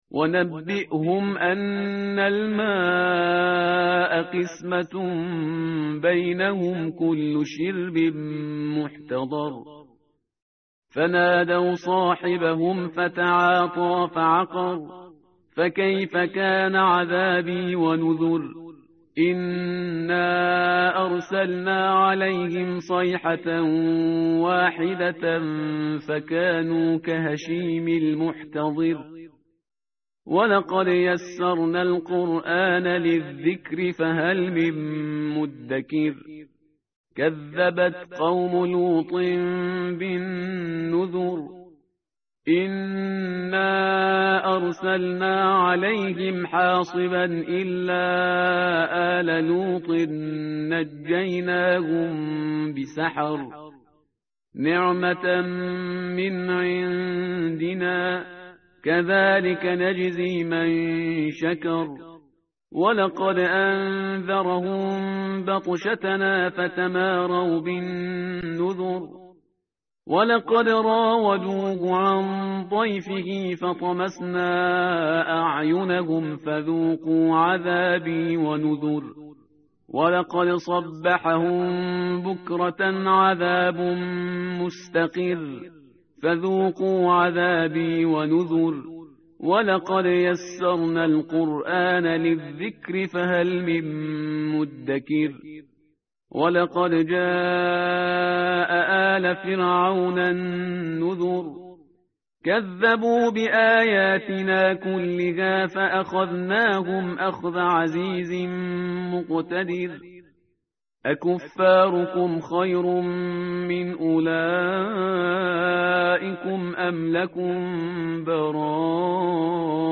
متن قرآن همراه باتلاوت قرآن و ترجمه
tartil_parhizgar_page_530.mp3